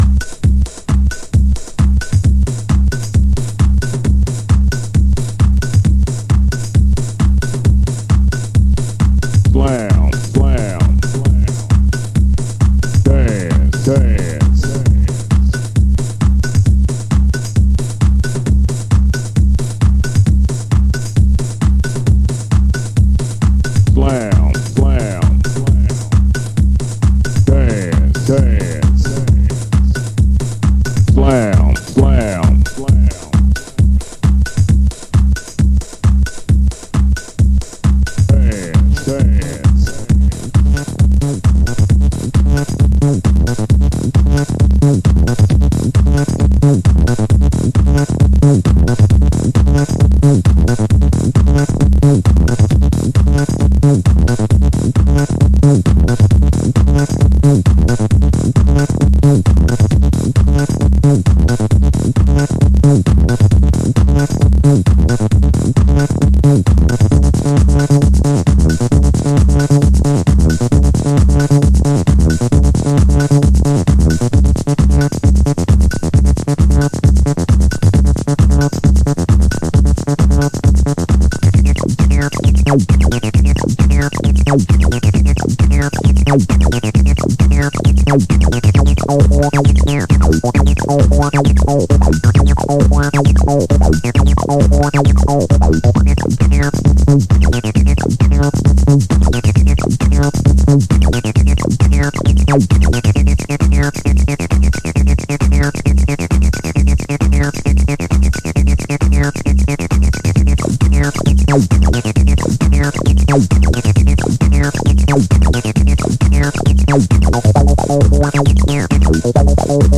303のピュアな捩れにグイグイ引き込まれるスラムダンス。